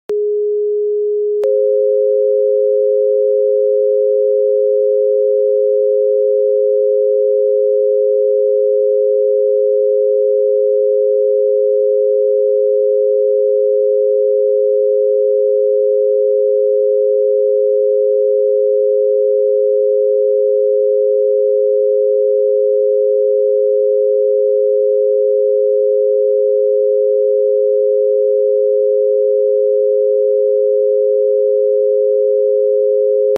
⚜ 417 + 528 Hz → 🌀 FREQUENCY OF ENERGETIC RESET & CELLULAR REGENERATION 🔥 This powerful merge purifies toxic fields, activates internal healing codes, and elevates your state of consciousness.